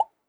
GenericNotification10a.wav